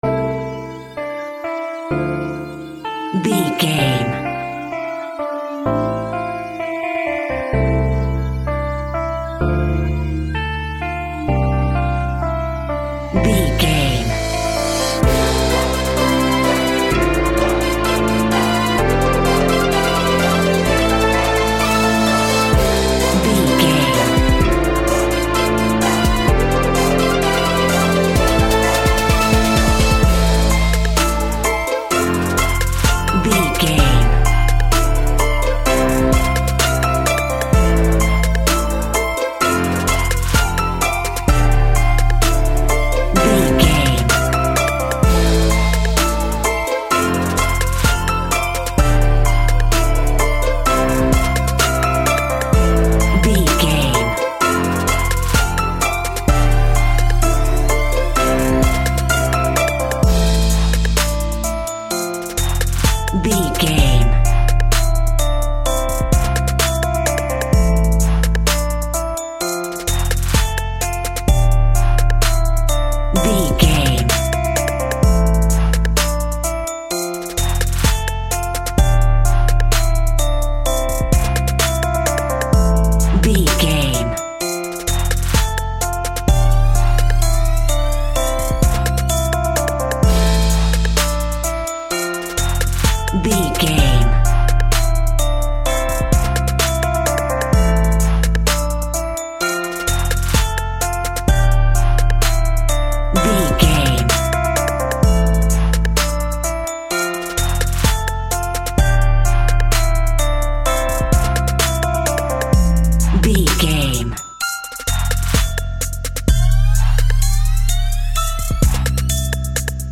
R+B Ballad Hip Hop.
Aeolian/Minor
chilled
laid back
hip hop drums
hip hop synths
piano
hip hop pads